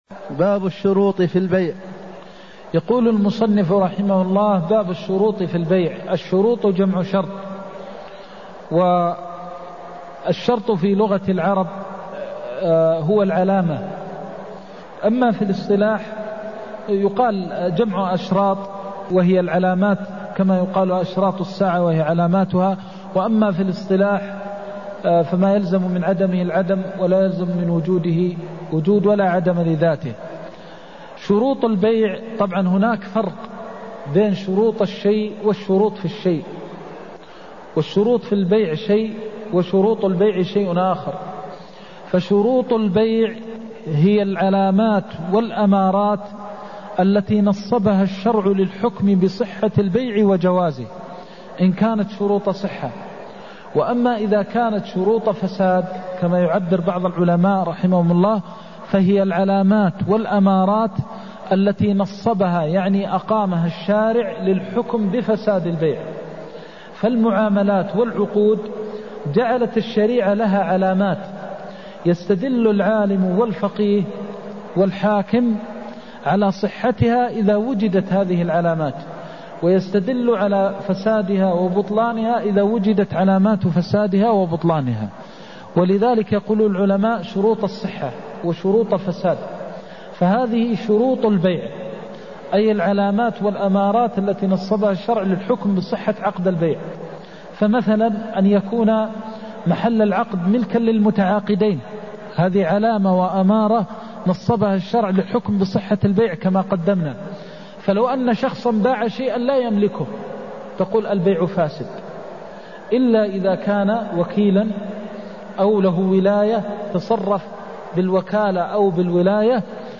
المكان: المسجد النبوي الشيخ: فضيلة الشيخ د. محمد بن محمد المختار فضيلة الشيخ د. محمد بن محمد المختار انما الولاء لمن اعتق (257)